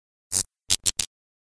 Звуки мерцания
Погрузитесь в мир загадочных аудиоэффектов.